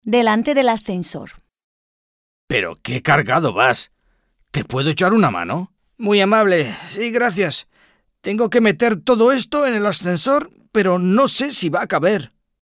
Dialogue - Delante del ascensor